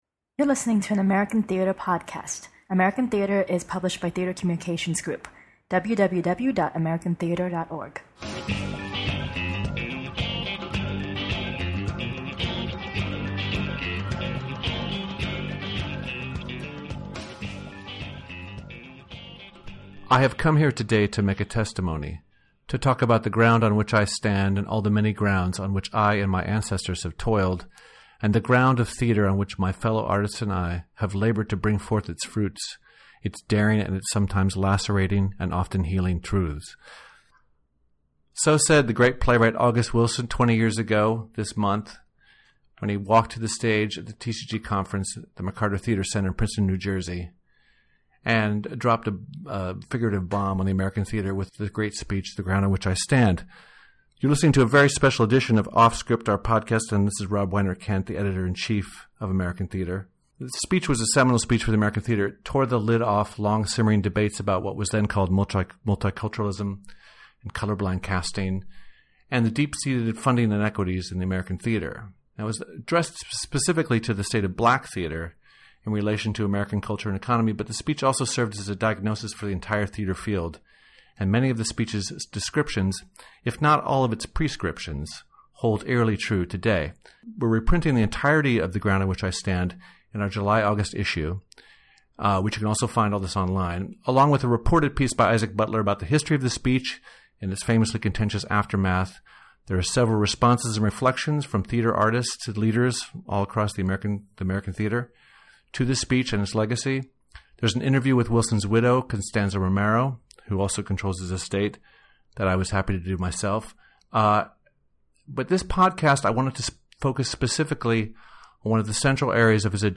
Every other week, the editors of American Theatre curate a free-ranging discussion about the lively arts in our Offscript podcast.